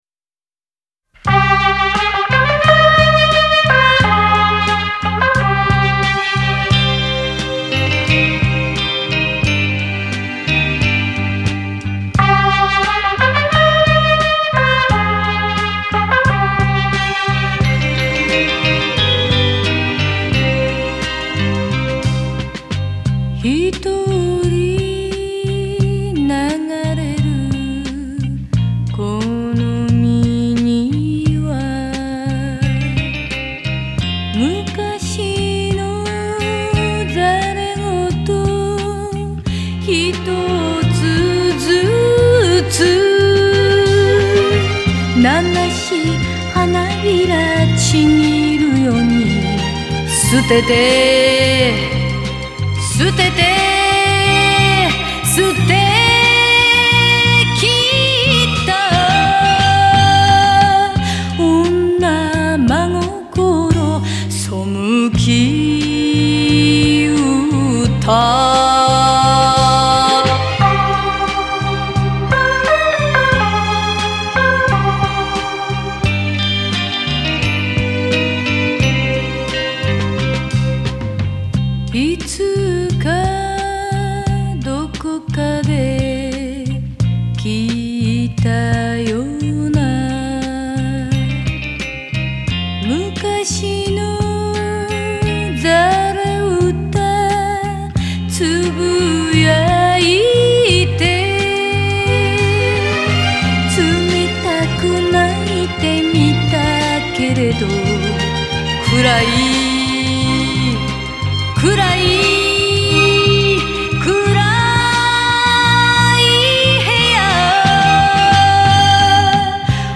Жанр: Enka / J-pop